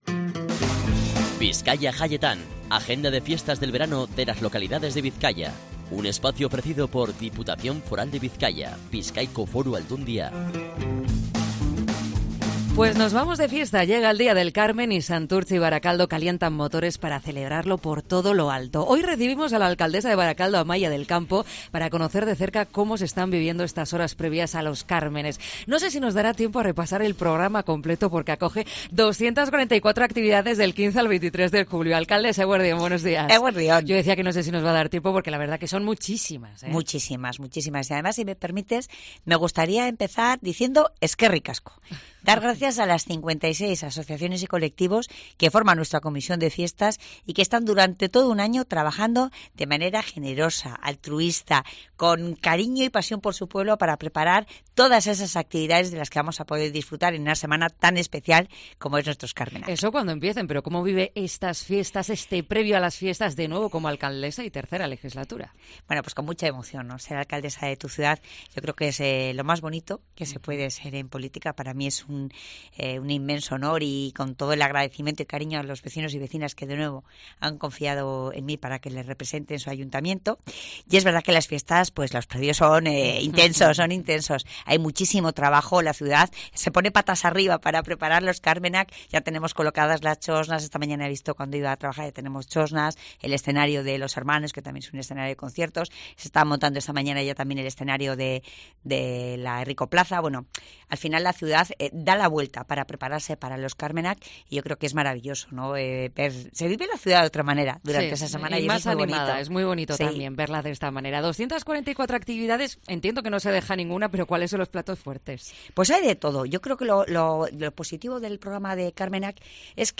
La alcaldesa de Barakaldo repasa las 244 actividades que acogerán las fiestas de la localidad a partir de este fin de semana
Entrevista a Amaia del Campo